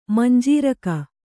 ♪ manjīraka